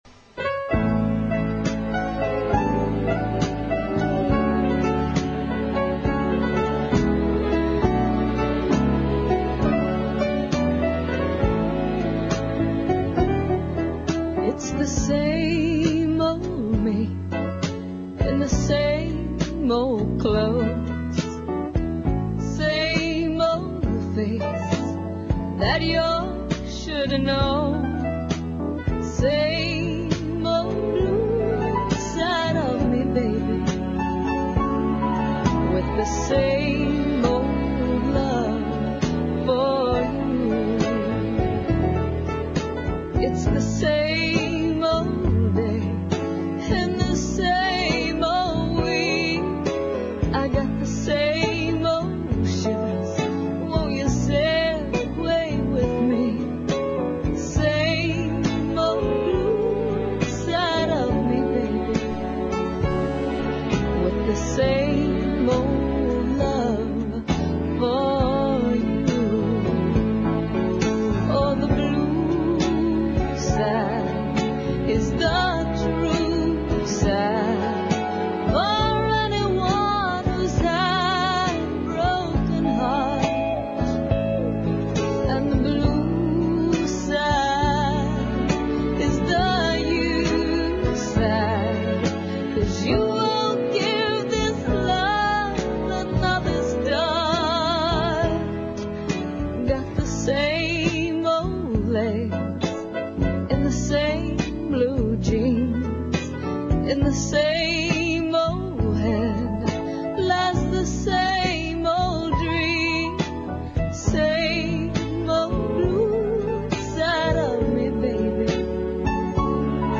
Crystal Gayle Interview on WSM nashville Oct.6th!